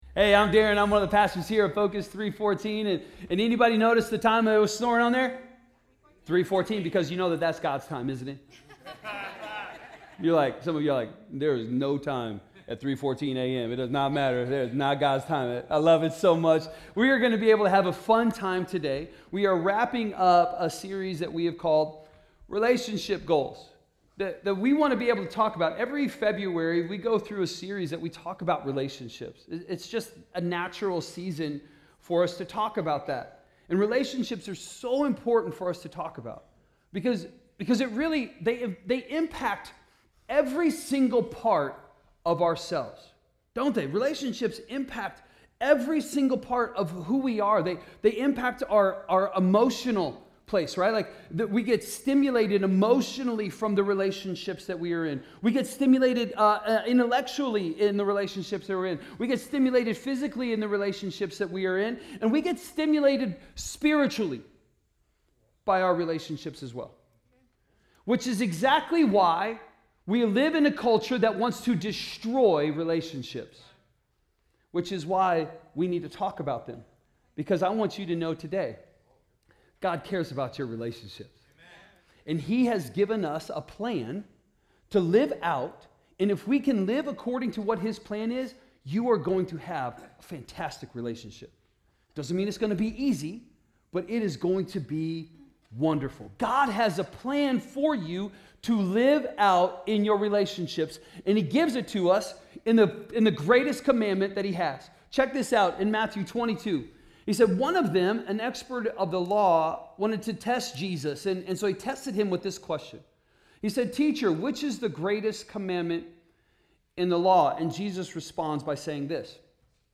A message from the series "Relationship Goals."